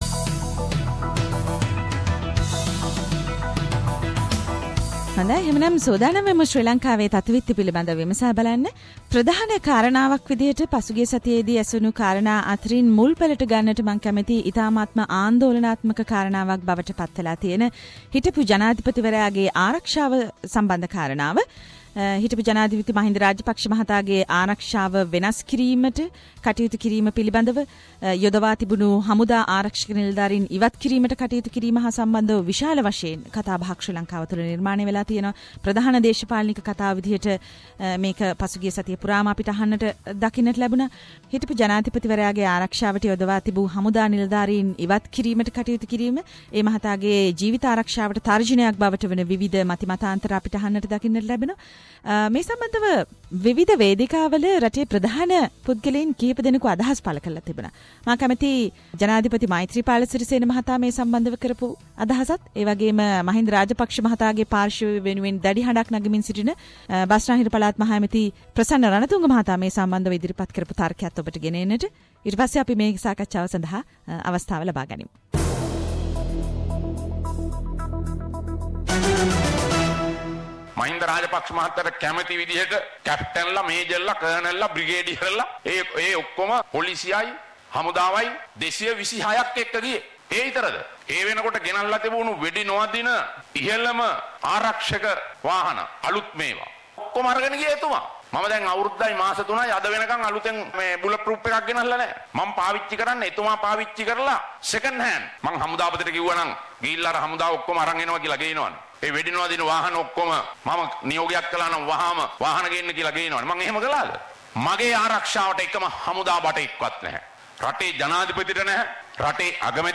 Journalist - (current affair) reports from Sri Lanka